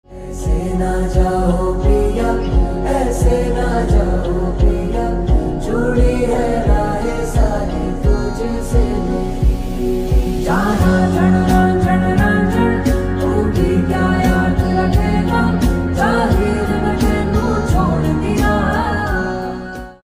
A Melodious Fusion
• Simple and Lofi sound
• Crisp and clear sound